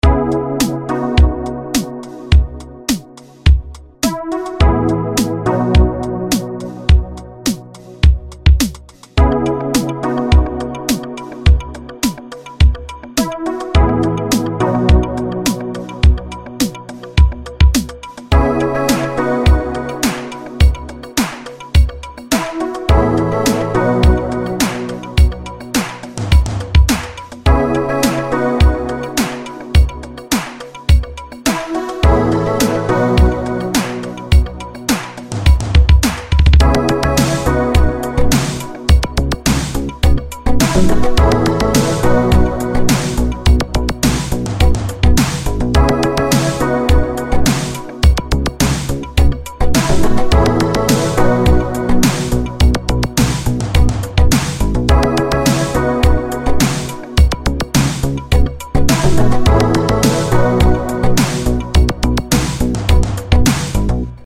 UVI plugin demo
Short demo using the UVX 10p, Drumulation, Emulation II, Beast Box, DSX, Vector Pro 22, and additional bass/picking guitars from Korg Polysix and Wavestation.